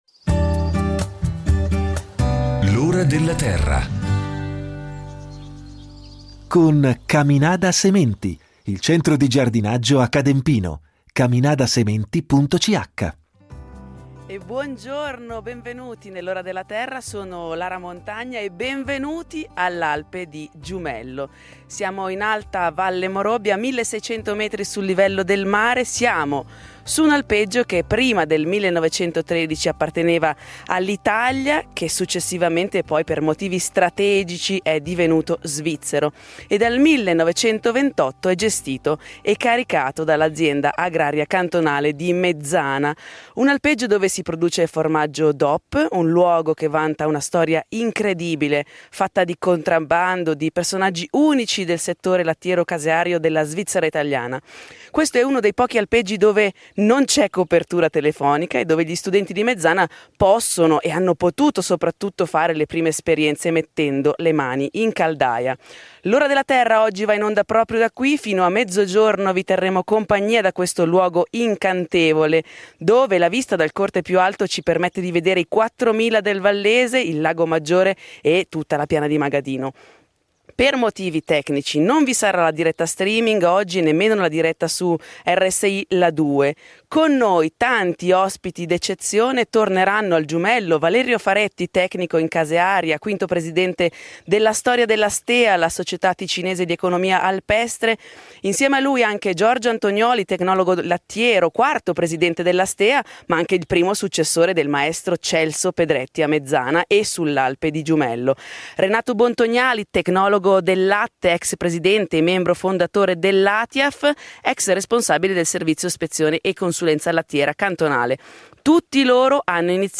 L’Ora della Terra andrà in onda proprio da qui, dalle 9:00 alle 12:00 domenica 4 luglio 2021.